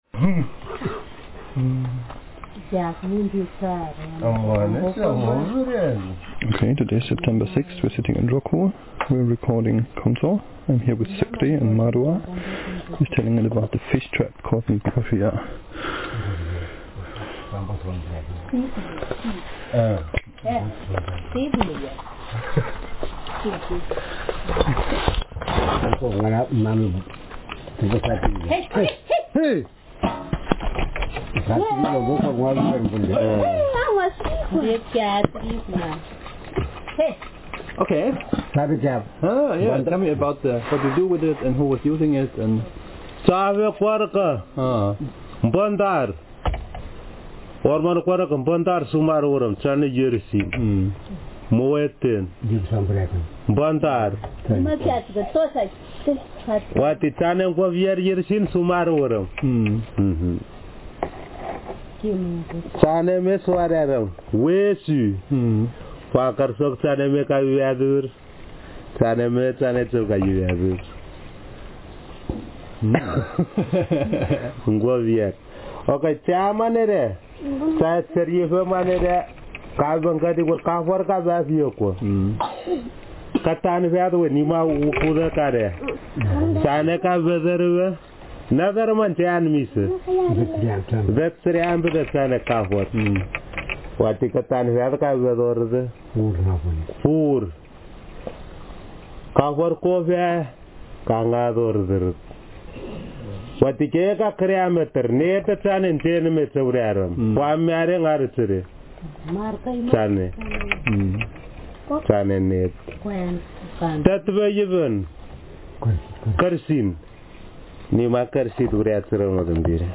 Speaker sex m/m Text genre conversation